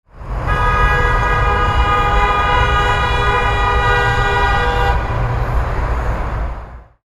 Car Passing With Horn Honk Sound Effect
Experience a car nervously honking while driving through a busy city street.
Street sounds.
Car-passing-with-horn-honk-sound-effect.mp3